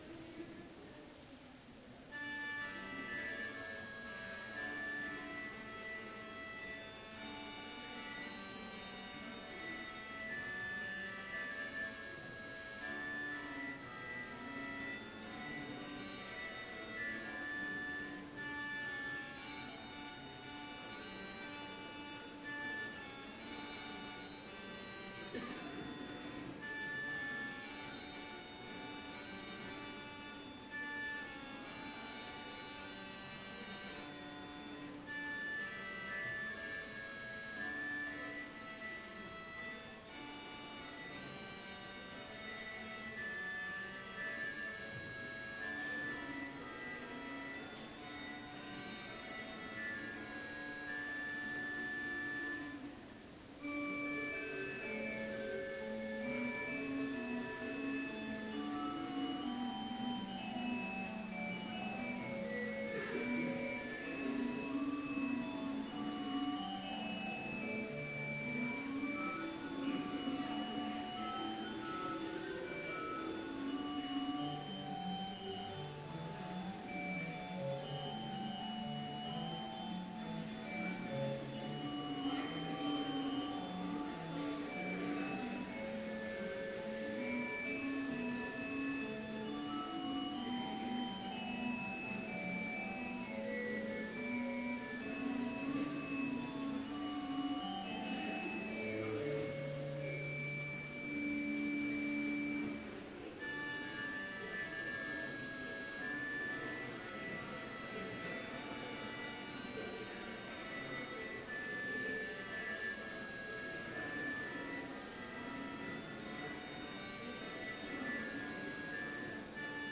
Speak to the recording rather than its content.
Chapel worship service in BLC's Trinity Chapel